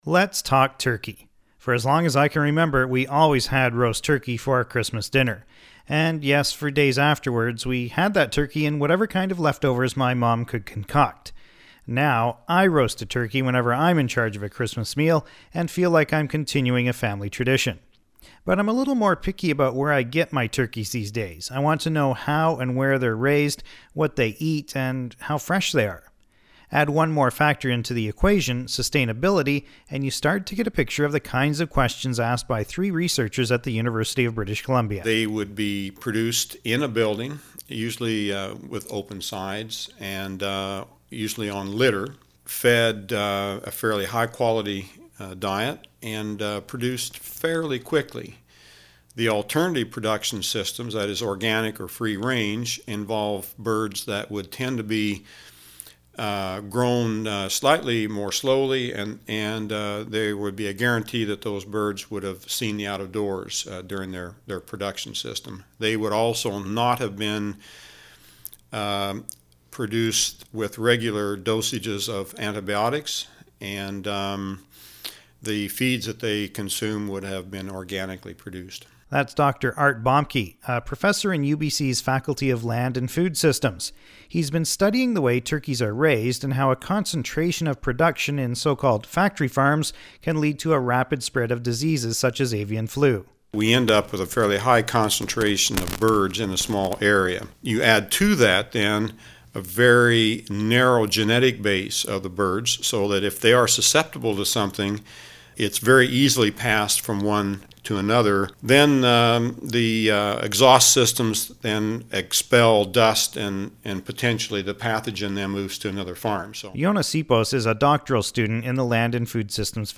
Turkey_1This week on Food For Thought, I talked turkey with three researchers who say you should think more about where your Christmas turkey comes from.  To listen to the 6-minute documentary as an mp3 file, click